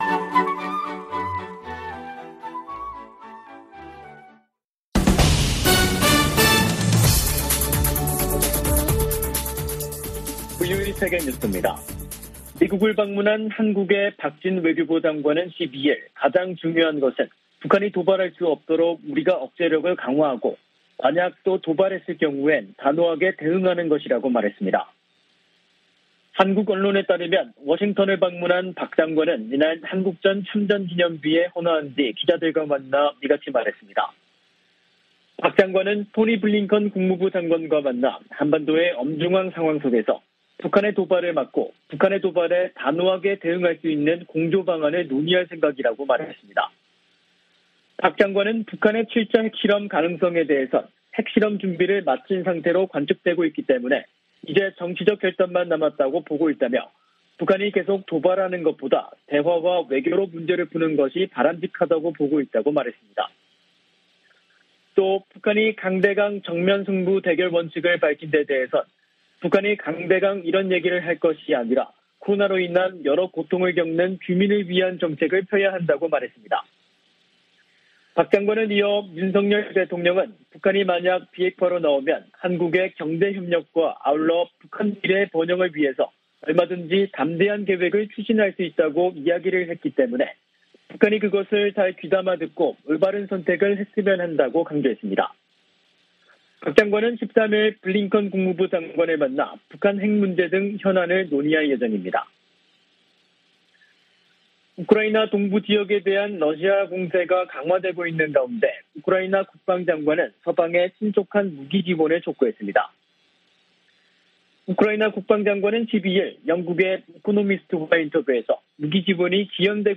VOA 한국어 간판 뉴스 프로그램 '뉴스 투데이', 2022년 6월 13일 3부 방송입니다. 김정은 북한 국무위원장이 10일 "대적 강대강 정면투쟁"의 강경기조를 천명한데 이어, 12일 북한이 방사포 무력시위를 벌였습니다. 미국과 일본, 호주 국방장관들이 북한의 핵무기 개발과 거듭된 미사일 발사를 강력 비판했습니다. 영국 국제전략문제연구소(IISS)는 미국과 한국의 대북 영향력은 갈수록 줄어드는 반면 중국의 영향력을 커지고 있다고 진단했습니다.